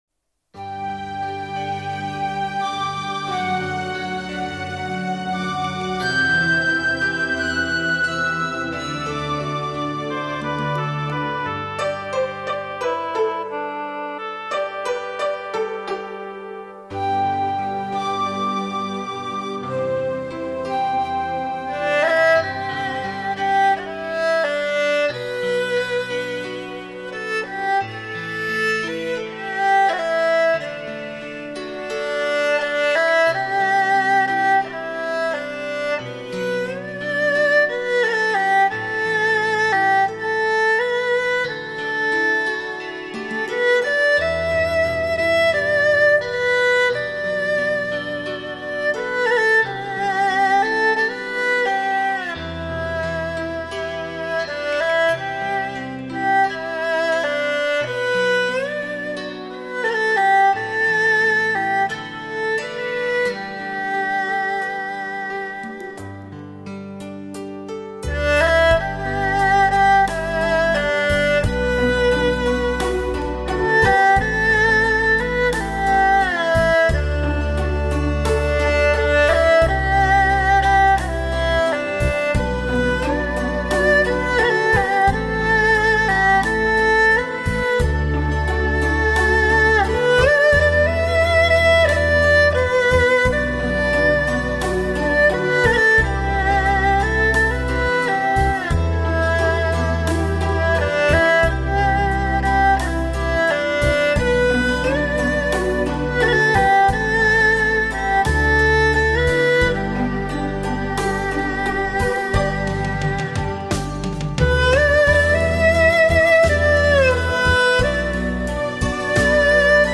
唱片类型：试机测试
二胡